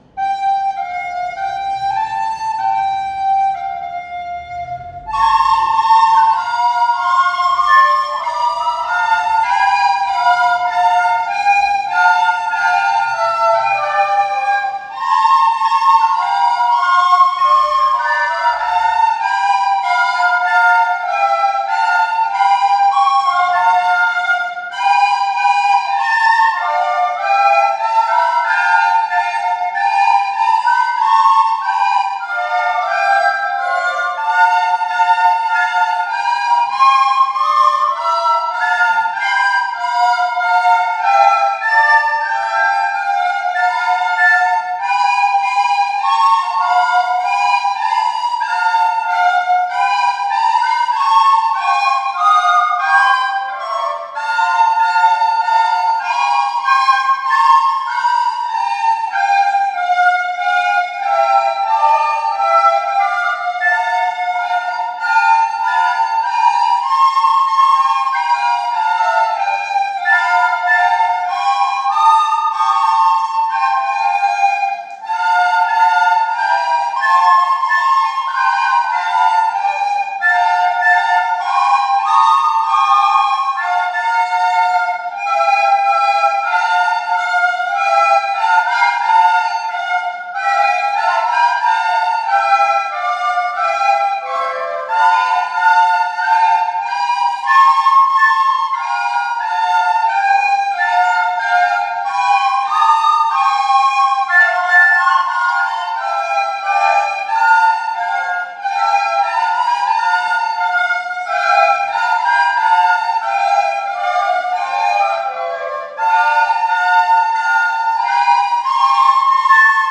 Στο πλαίσιο του έργου μας eTwinning, επιλέξαμε να τραγουδήσουμε αυτό το διαχρονικό κομμάτι
Η ηχογράφηση του σχολείου μας είναι παρακάτω: